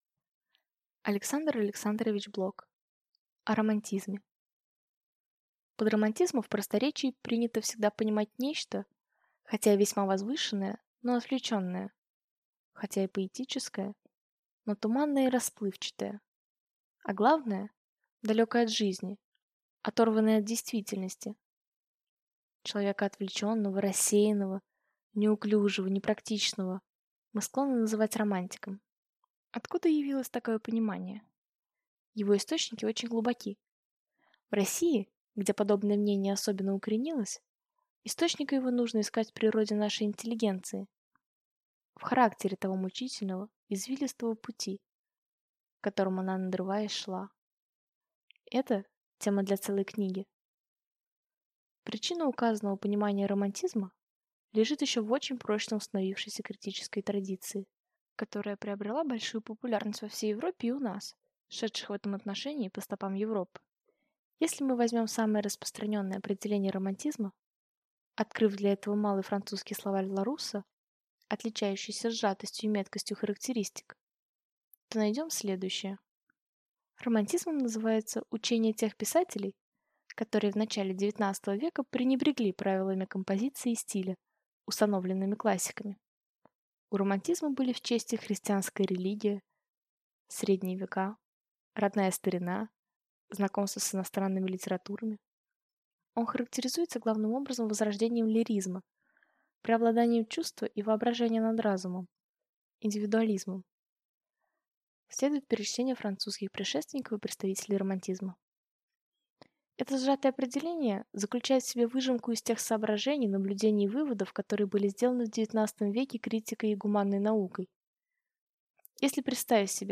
Аудиокнига О романтизме | Библиотека аудиокниг